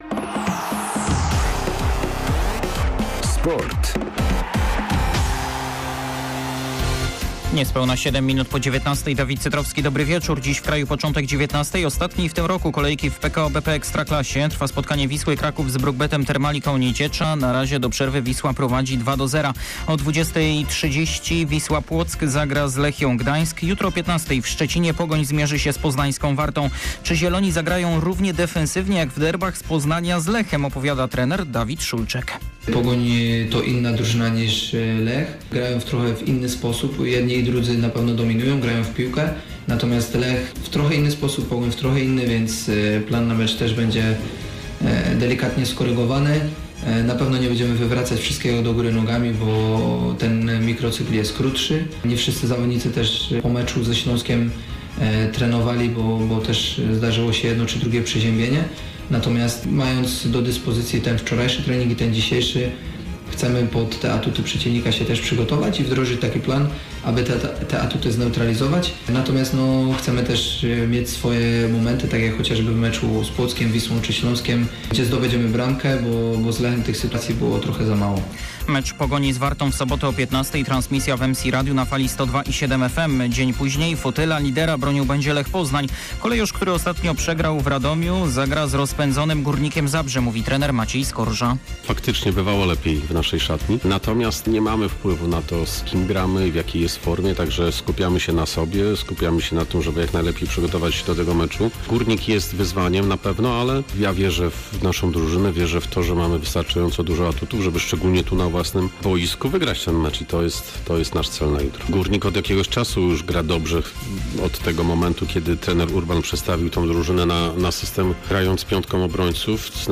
17.12.2021 SERWIS SPORTOWY GODZ. 19:05